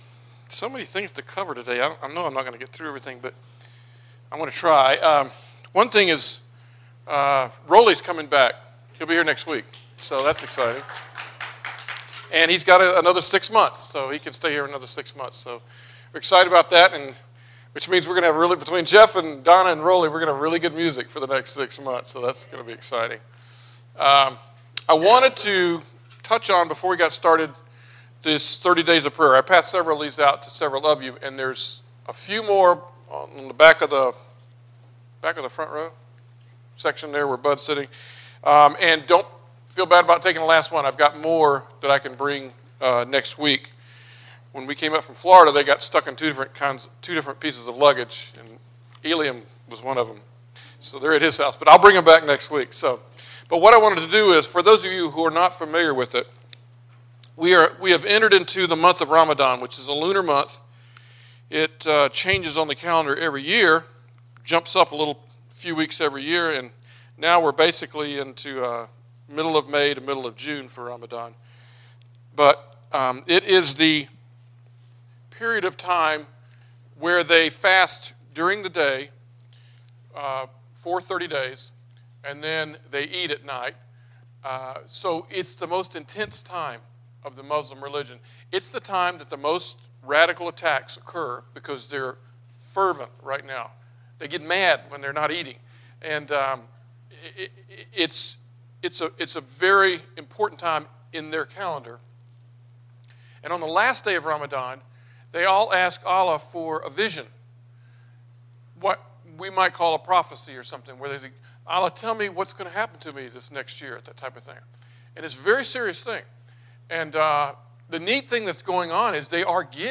Sermons - template